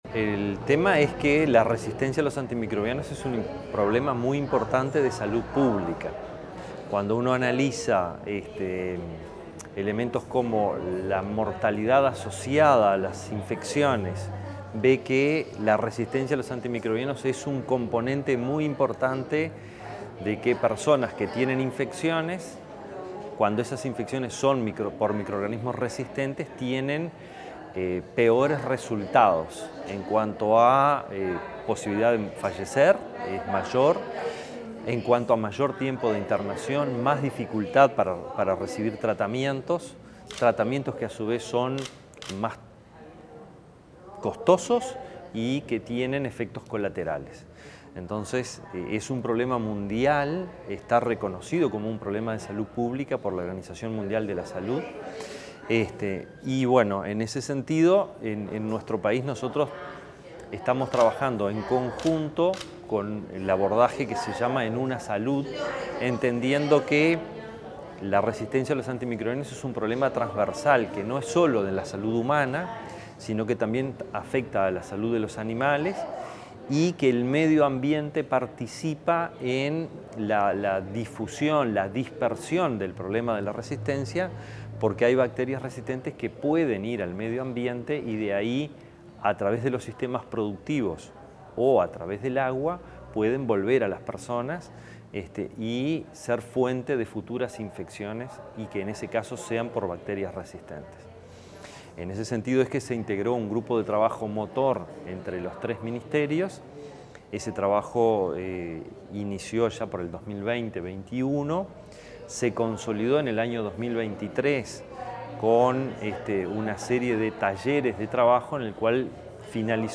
Declaraciones